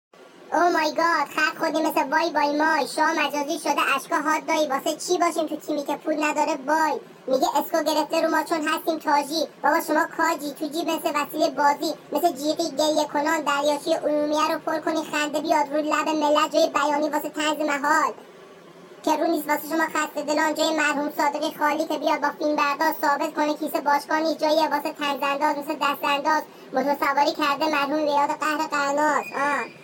🔥🔥🔥🔥 دیس واسه خط خوردن کیسه کش ها🔥🔥🔥🔥 همراه با متن:) و